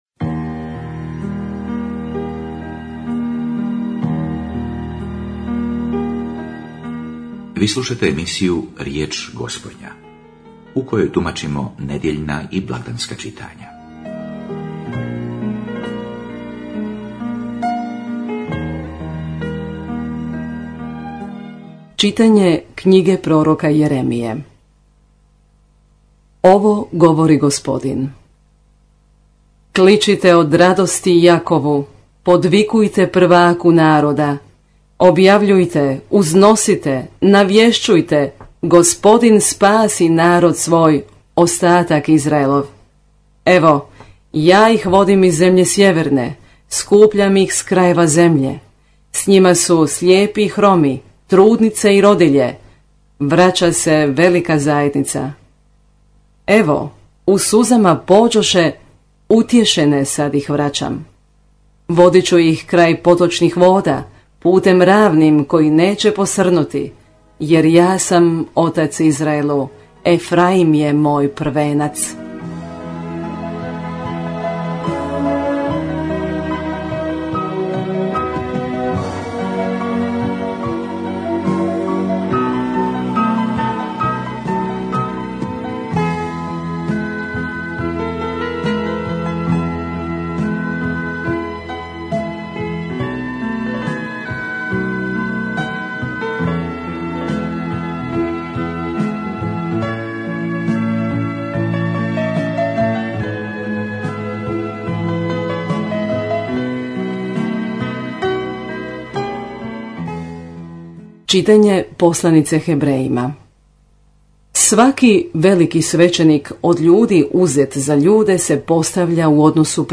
homilija